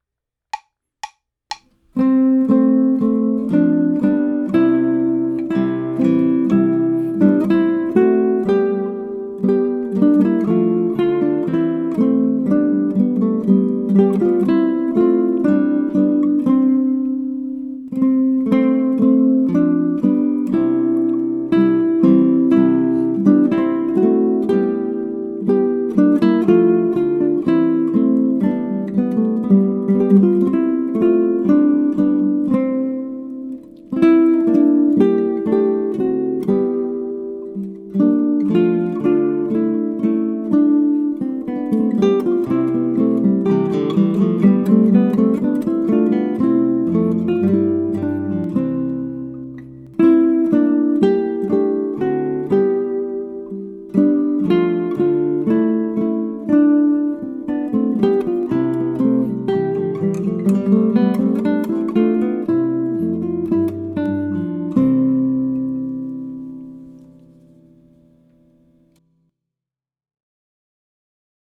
Now Is The Month Of Maying, by Thomas Morley (1557-1602), was composed during the late Renaissance, the time of Shakespeare and the King James Bible.
Now Is The Month Of Maying is a trio, a piece with three separate guitar parts: guitar 1 (melody), guitar 2 (harmony) and guitar 3 (bass).
Now Is The Month Of Maying evokes Springtime joy and, thus, should be played at an allegro (cheerful) tempo.